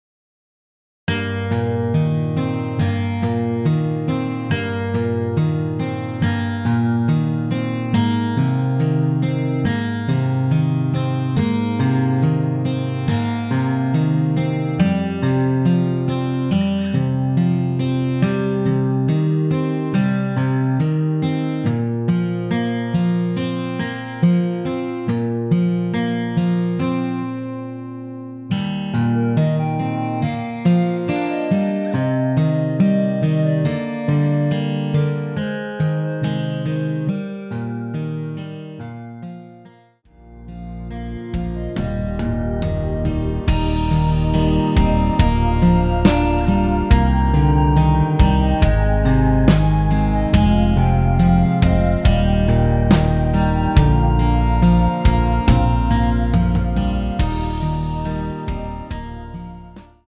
원키에서(+8)올린 멜로디 포함된 MR입니다.
Eb
앞부분30초, 뒷부분30초씩 편집해서 올려 드리고 있습니다.
중간에 음이 끈어지고 다시 나오는 이유는